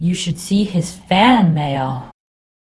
Text-to-Speech
more clones